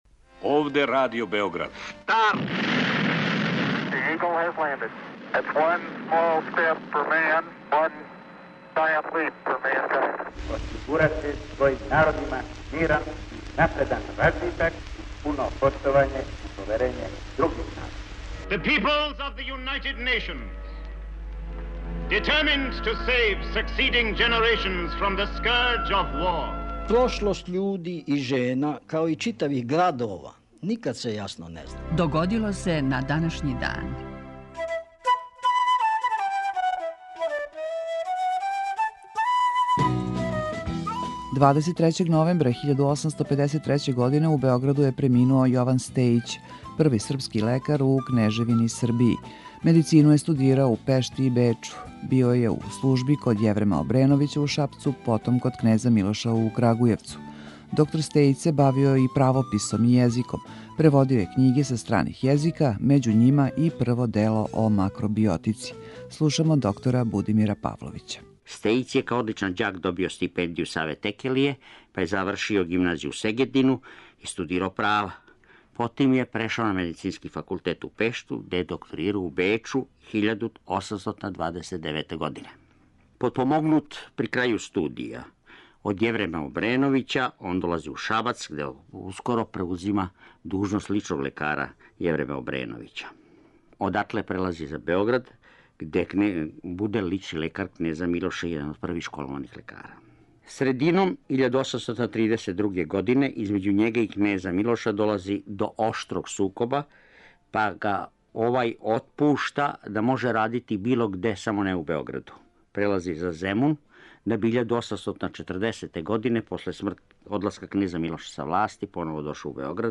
У 5-томинутном прегледу, враћамо се у прошлост и слушамо гласове људи из других епоха.